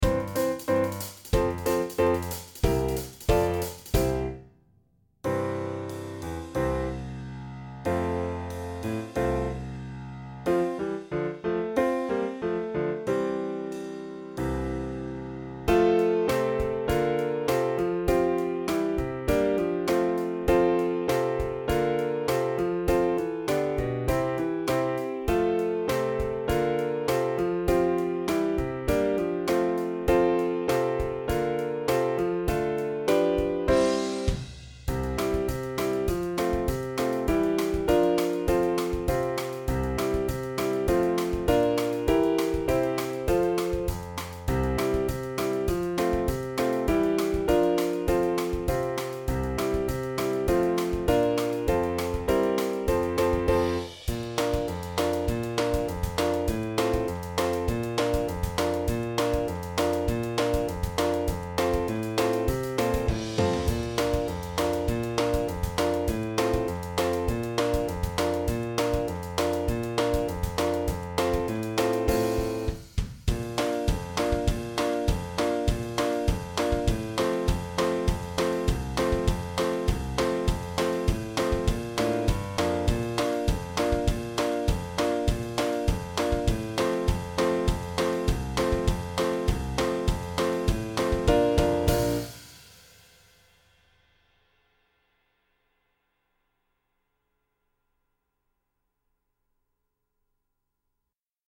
TROMBONE SOLO • ACCOMPAGNAMENTO BASE MP3